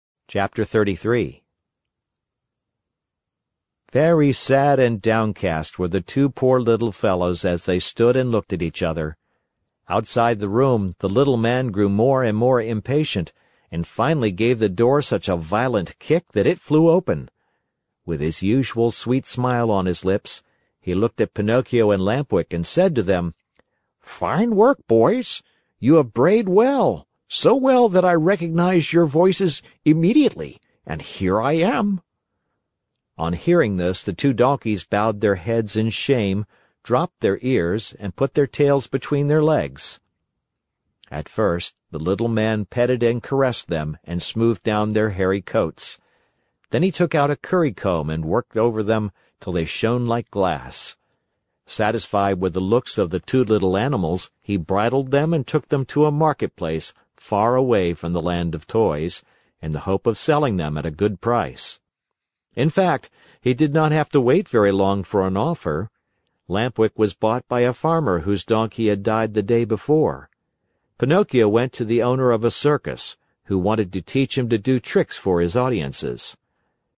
在线英语听力室木偶奇遇记 第129期:匹诺曹登台献艺(1)的听力文件下载,《木偶奇遇记》是双语童话故事的有声读物，包含中英字幕以及英语听力MP3,是听故事学英语的极好素材。